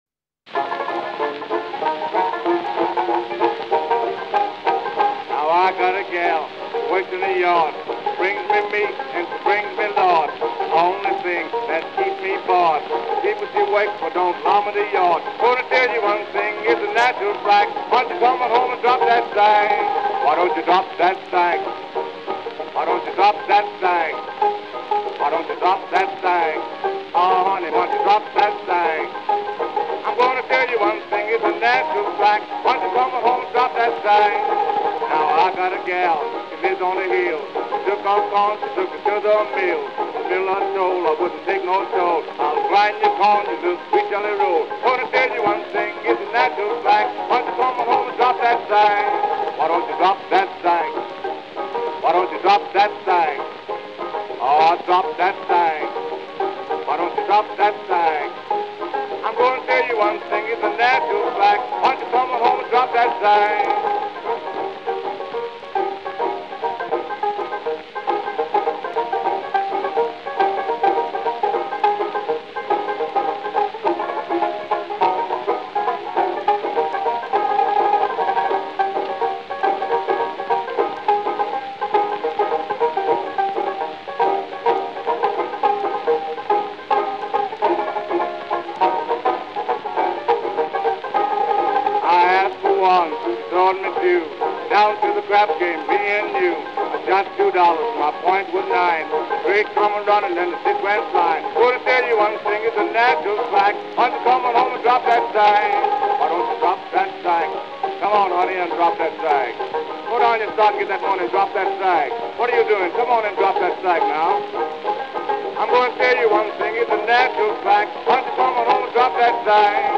Papa Charlie Jackson (1887-1938) provided a bridge between ragtime and blues, and has the distinction of being one of the creators of “Hokum” – songs filled with sexual euphemisms and innuendo (Drop That Sack is one).